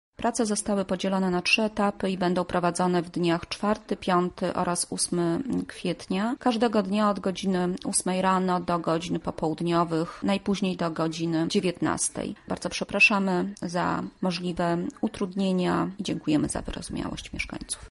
O remoncie mówi rzecznik prasowy